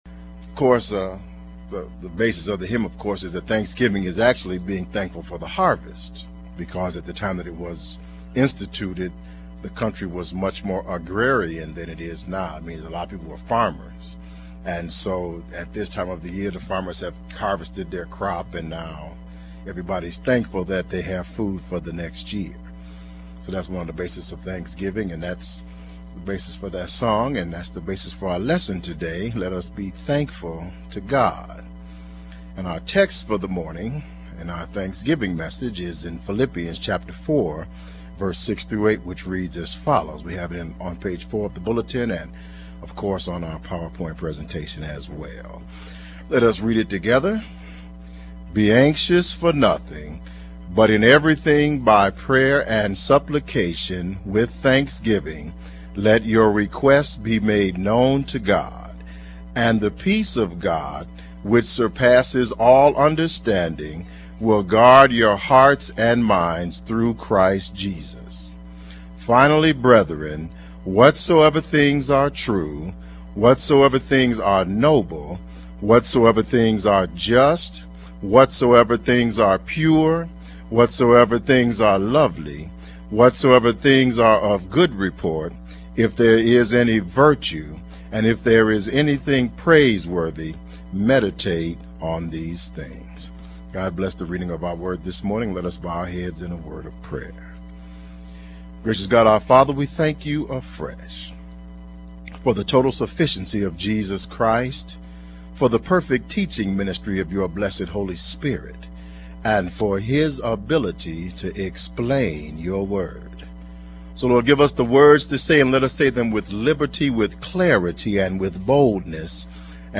Audio Download: Click to download Audio (mp3) Additional Downloads: Click to download Sermon Text (pdf) Content Feeds Use the links below to subscribe to our regularly produced audio and video content.